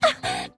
Worms speechbanks
OW3.WAV